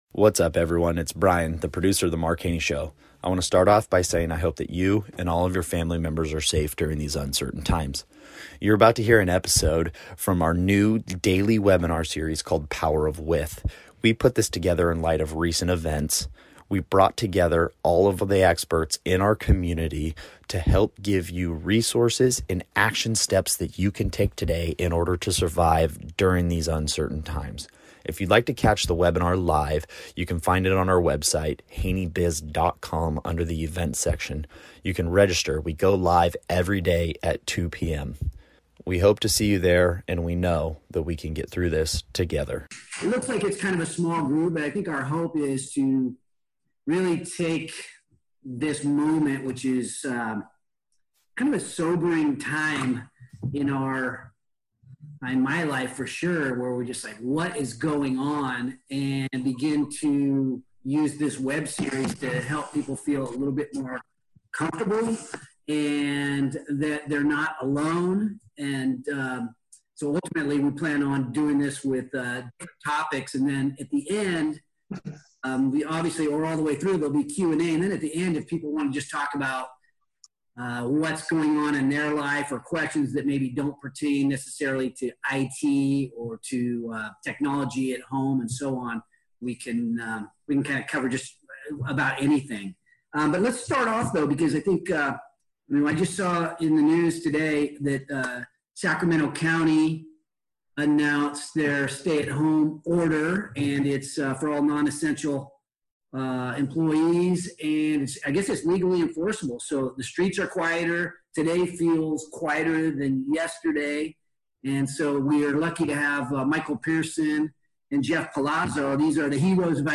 How to Setup and Manage a Remote Workforce | HaneyBiz Daily Webinar Series